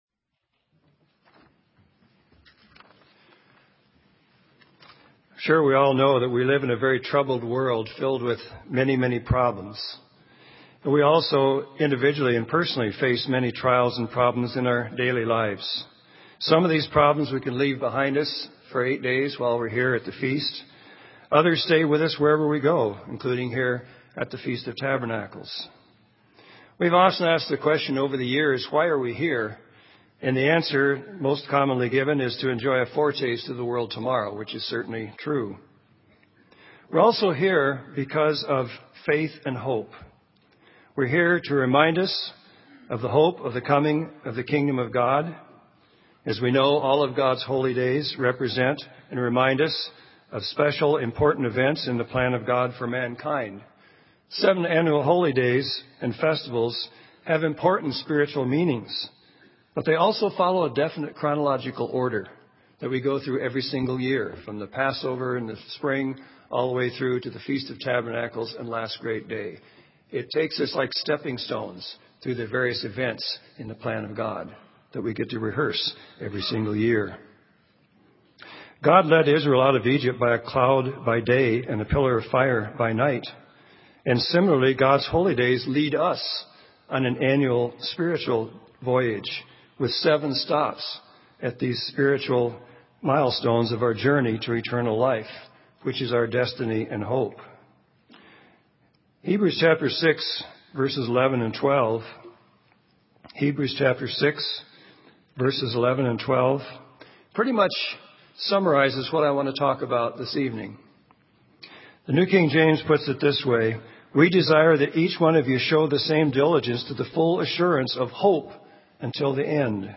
This sermon was given at the Bend, Oregon 2011 Feast site.